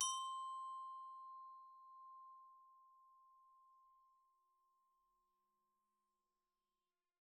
glock_medium_C5.wav